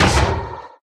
Minecraft Version Minecraft Version 1.21.5 Latest Release | Latest Snapshot 1.21.5 / assets / minecraft / sounds / mob / irongolem / hit1.ogg Compare With Compare With Latest Release | Latest Snapshot